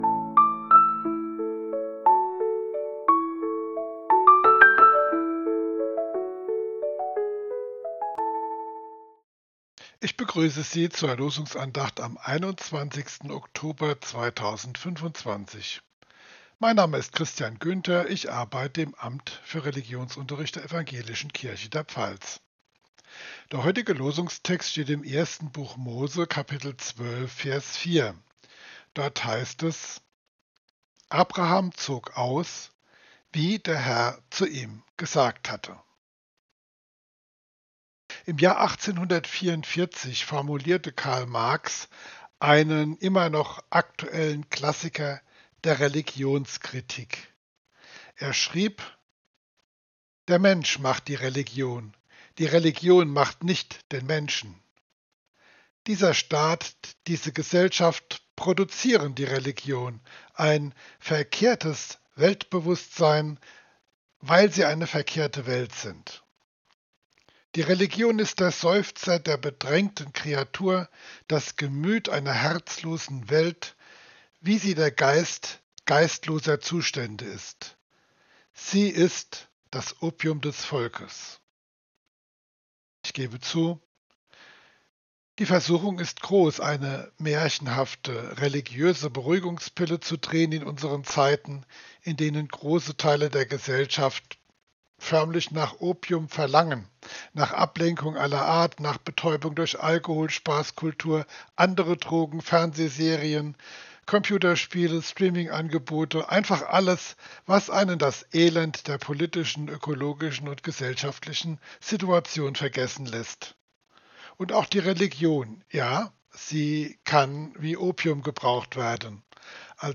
Losungsandacht für Dienstag, 21.10.2025